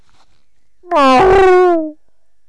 bull_die2.wav